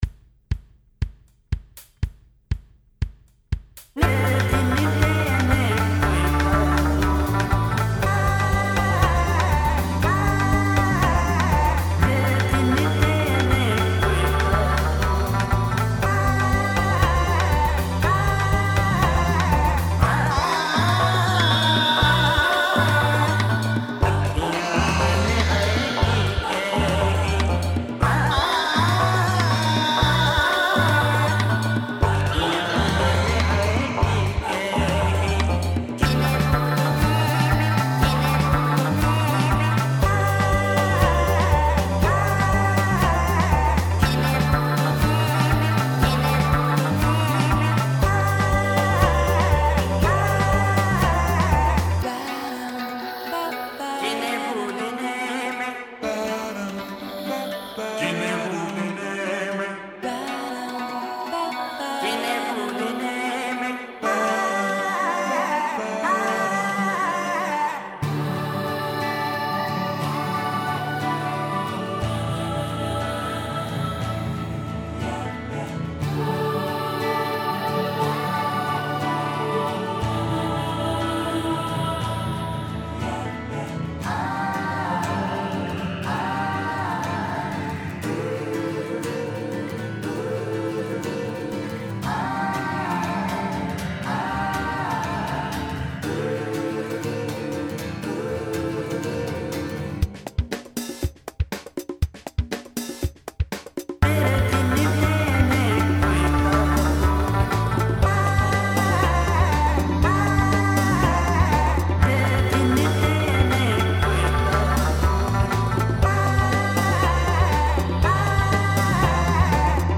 Voices (synthesised vocals only) - Orchestrated 17th-22nd March 2012.
This early track was an idea I'd had for some time to make a complete track from pre-recorded vocals i.e. vocal loops.
The sounds at the end are intended to be a choir jumping into a swimming pool (how's that for odd?).